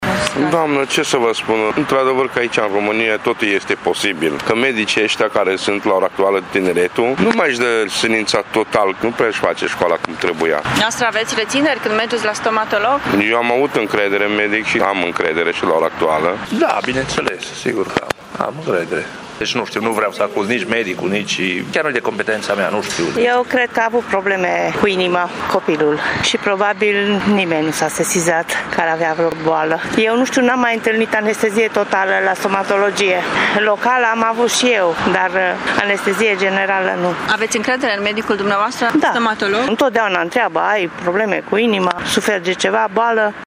Târgumureșenii cred că nu se cunosc toate datele tragediei de la Pitești în care un copil de 4 ani a murit în urma unei anestezii generale, dar au încredere în medicii lor :